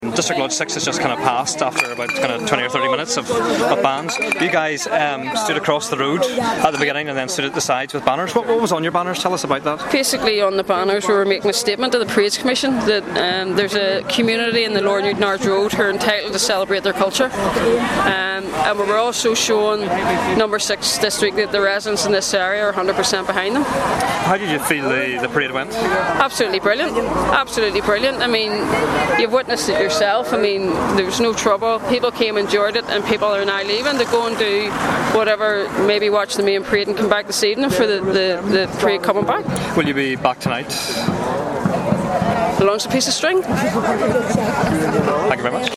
Protesters at LOL6 district parade in East Belfast